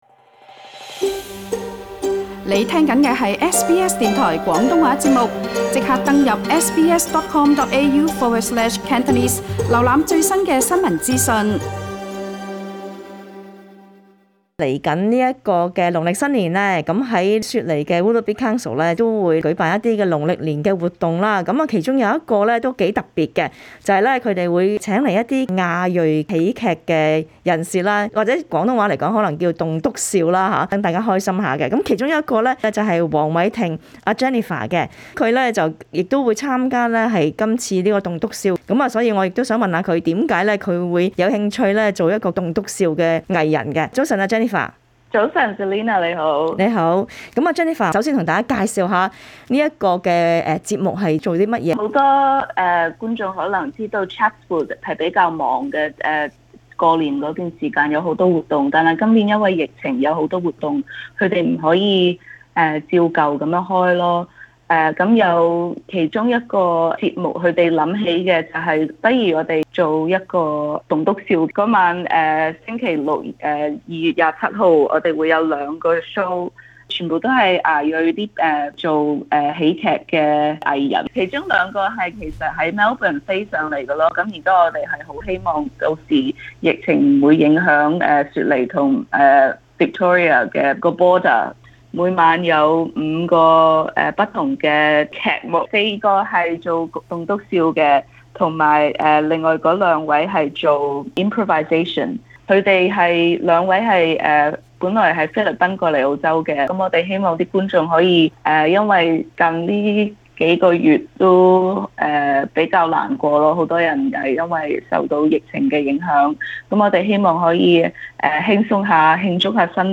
詳情請聽這節【社區專訪】。